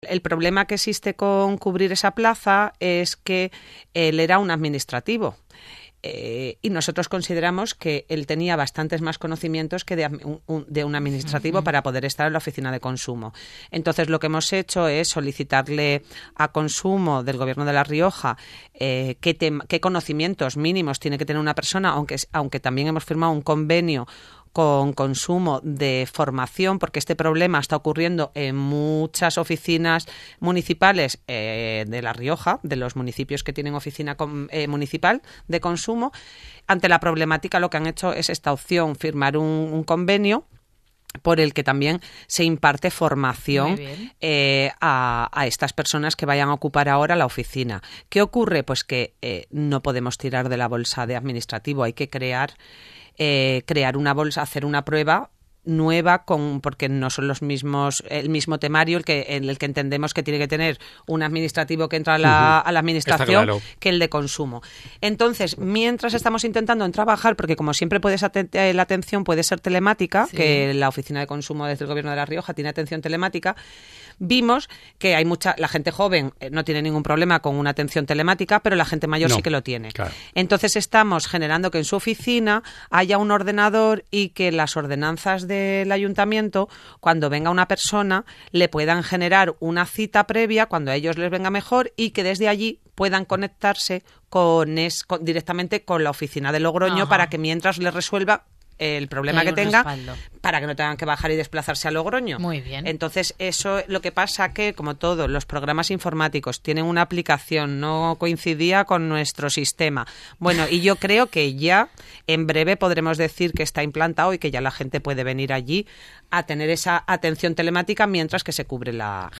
Así lo ha destacado la alcaldesa de Haro.